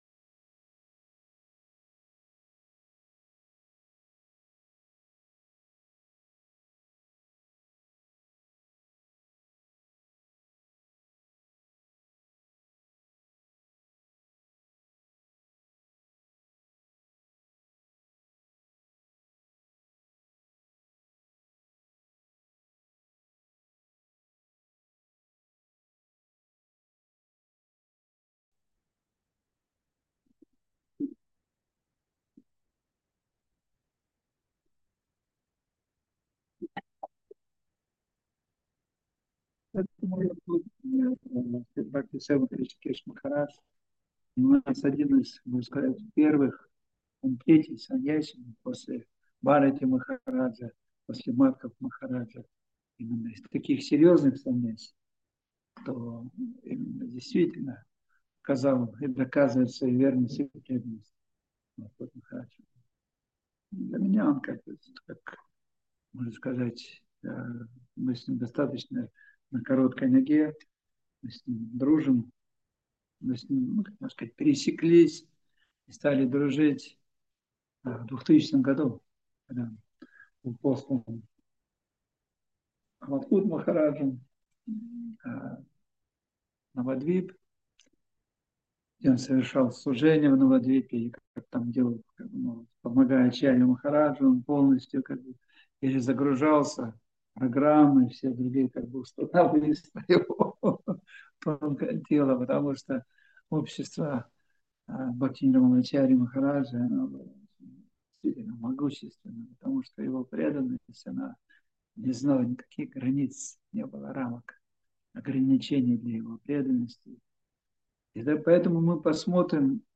Москва, Кисельный
Лекции полностью